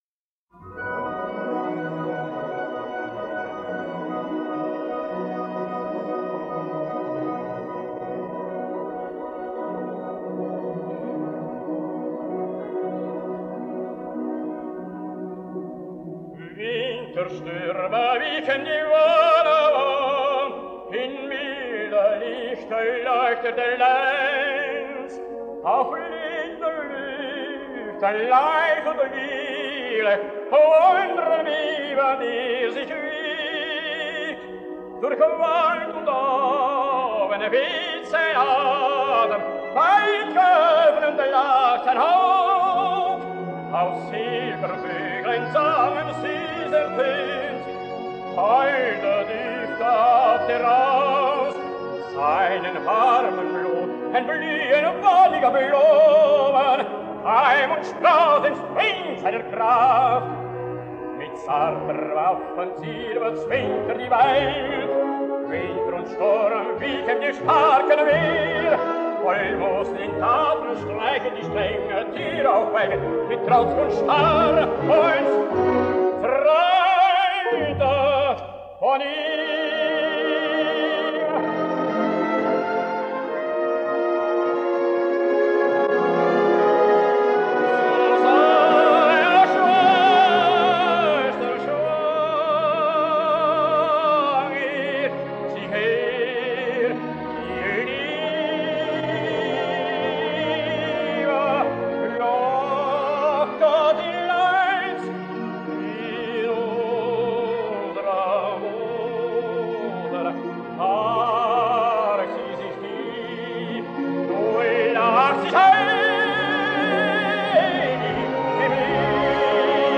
After WWII was over, he was the first singer to be hired by the Antwerp opera to form a new troupe; now, he was a heldentenor, specializing in Wagner, but also singing Peter Grimes, Idomeneo, Samson or Otello, not to forget Flemish operas by Blockx, De Boeck or Wambach.